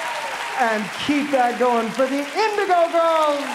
lifeblood: bootlegs: 2020-02-15: the town hall - new york, new york (live from here with chris thile)
(captured from a youtube live stream)